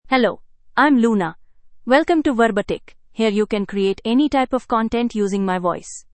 LunaFemale English AI voice
Luna is a female AI voice for English (India).
Voice sample
Listen to Luna's female English voice.
Female
Luna delivers clear pronunciation with authentic India English intonation, making your content sound professionally produced.